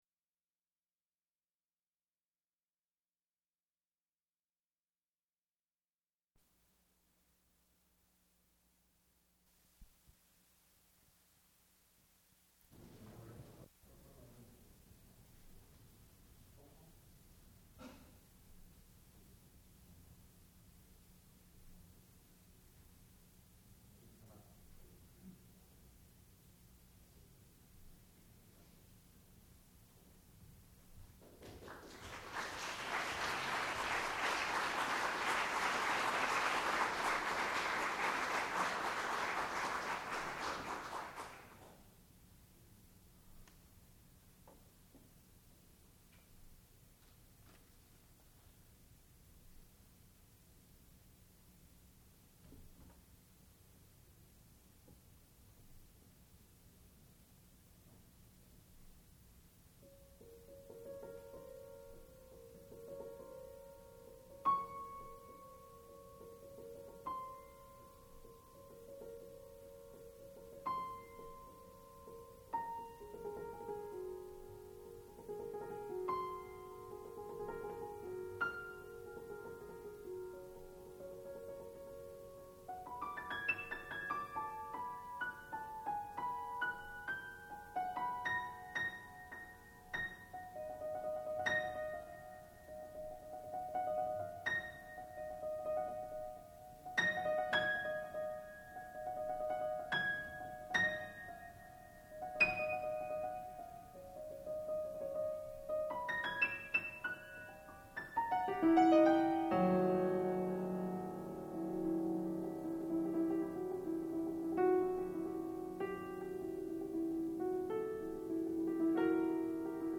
sound recording-musical
classical music